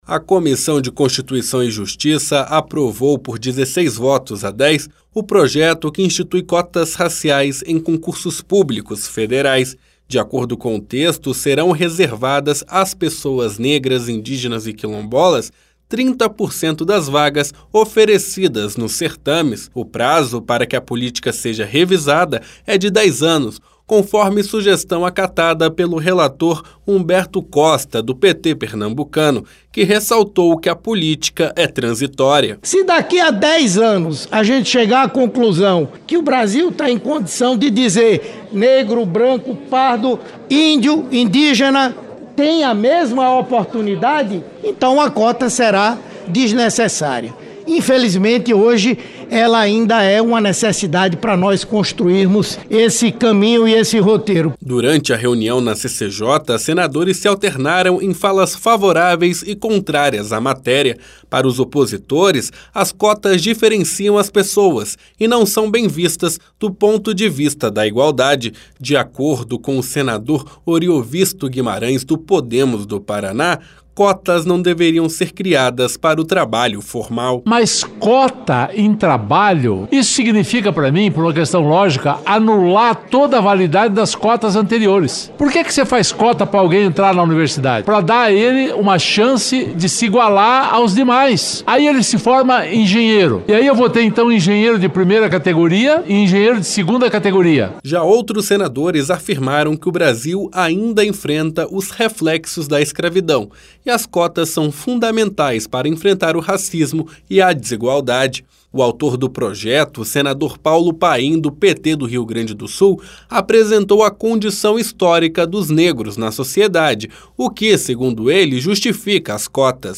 Nesta quarta-feira (24), a Comissão de Constituição e Justiça aprovou o projeto que reserva 30% das vagas em concursos públicos federais para negros, indígenas e quilombolas (PL 1958/2021). Senadores se alternaram em falas favoráveis e contrárias à matéria. Para Oriovisto Guimarães (Podemos-PR), cotas não são necessárias para trabalho formal.